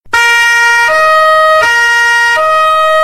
Police
police.mp3